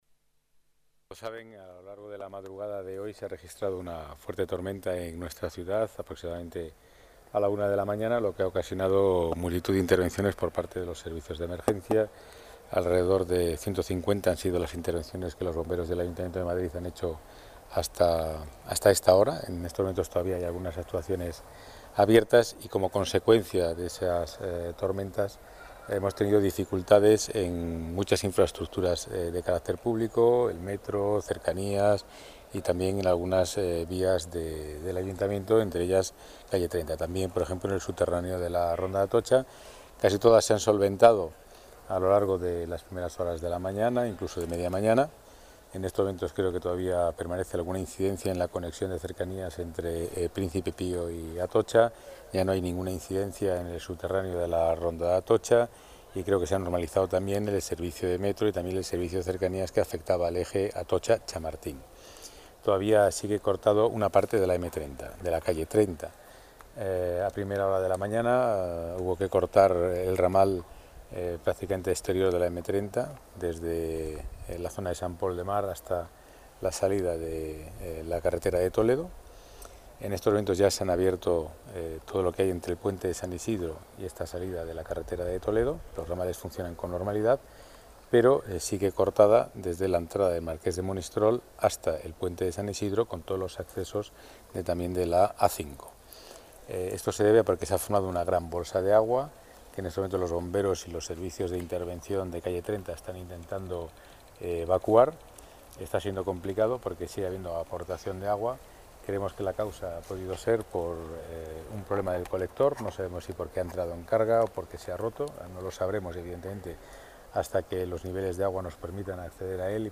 Nueva ventana:Declaraciones delegado Seguridad, Pedro Calvo: incidencias tormenta granizo